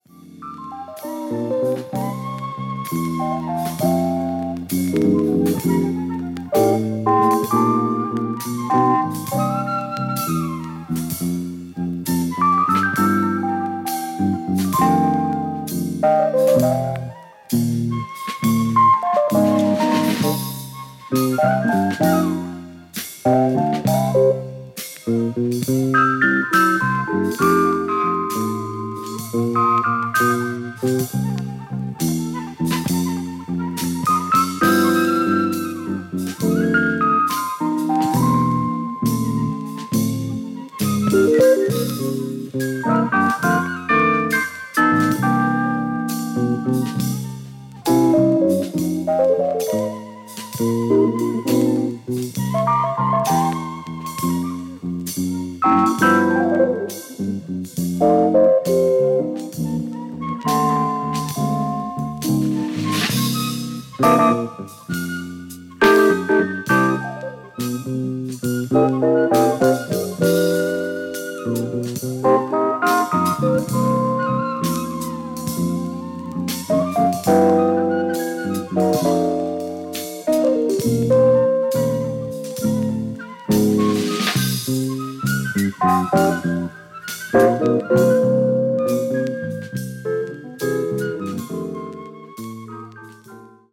Condition Media : VG-(Side-A・中心ズレ)
Jazz鍵盤奏者